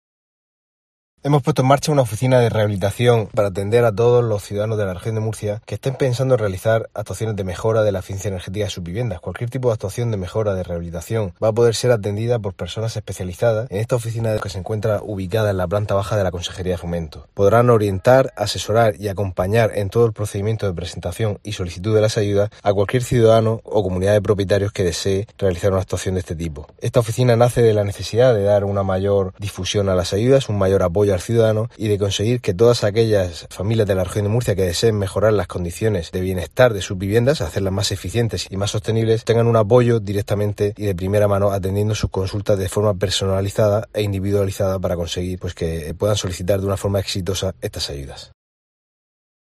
José Francisco Lajara, director general de Vivienda